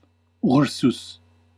Ääntäminen
Ääntäminen Classical: IPA: /ˈur.sus/ Haettu sana löytyi näillä lähdekielillä: latina Käännös Ääninäyte Substantiivit 1. björn {en} Suku: m .